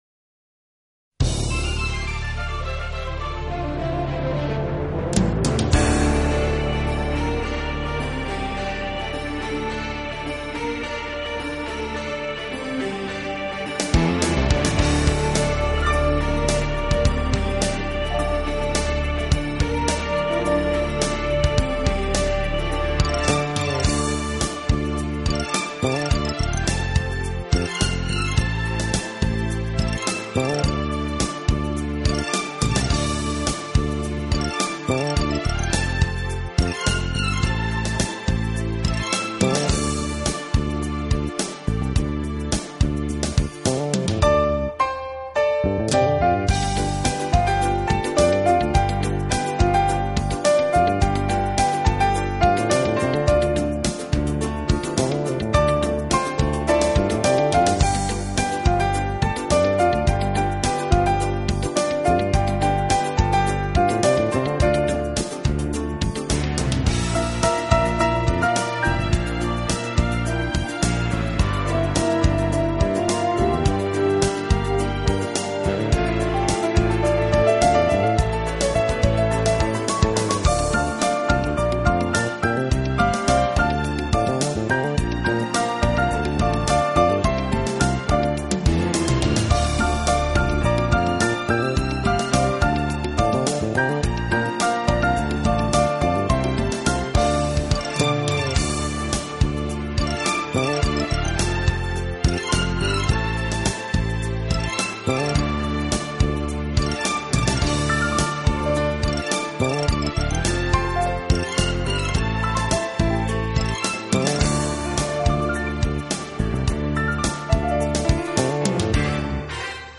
Genre : Instrumental